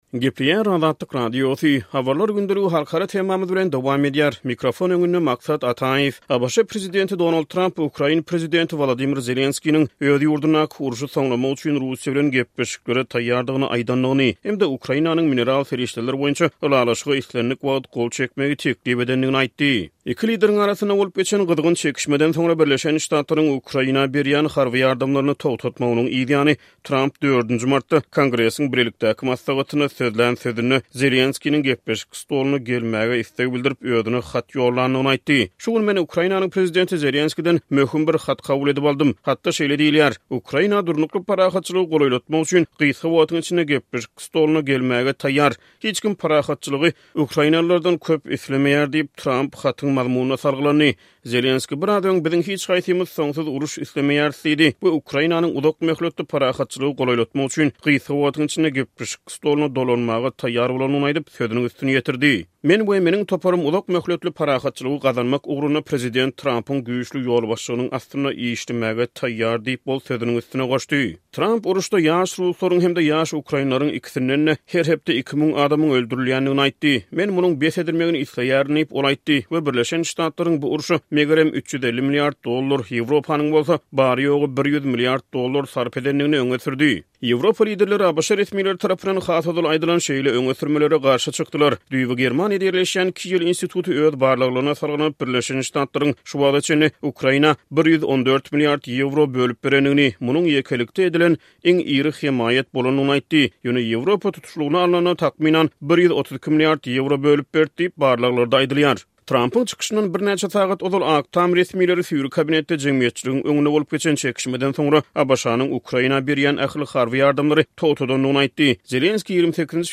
Diňle: Tramp Kongresdäki çykyşynda Zelenskiniň mineral serişdeler ylalaşygyna 'islendik wagt' gol goýmak barada beren teklibine salgylandy